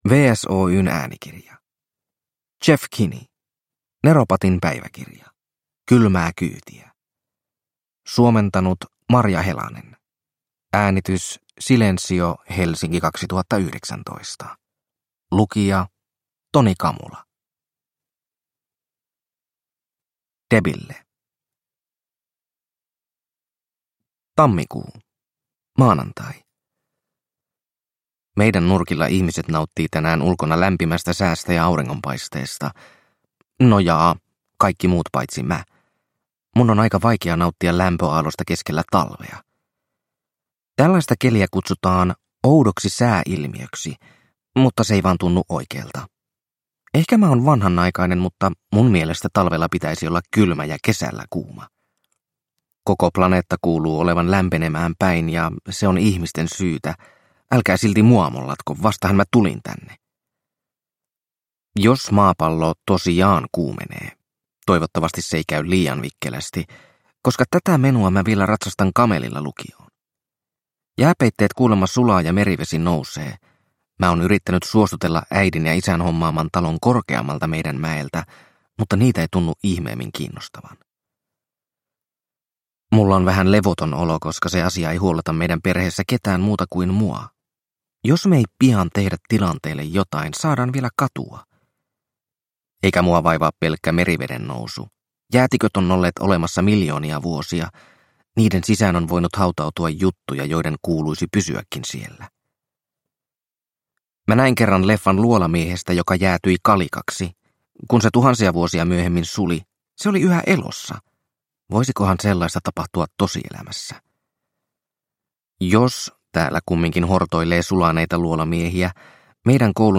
Neropatin päiväkirja: Kylmää kyytiä – Ljudbok – Laddas ner